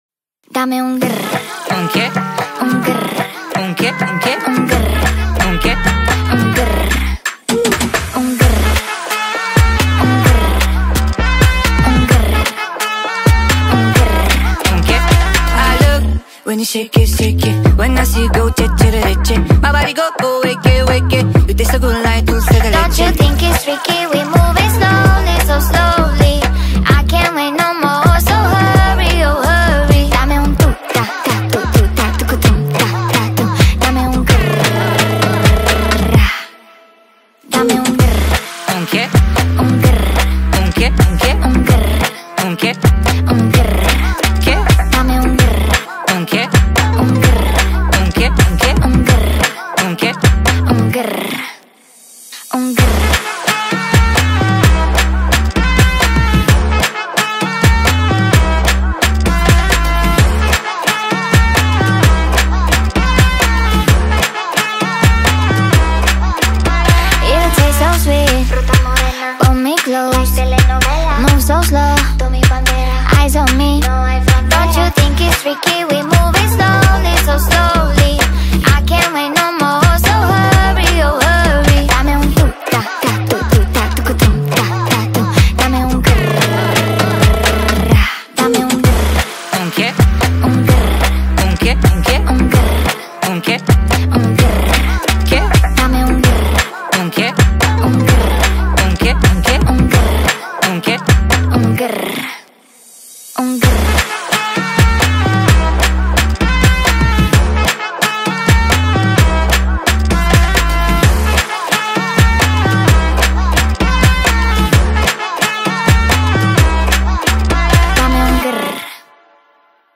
در نسخه Sped Up و سریع شده
شاد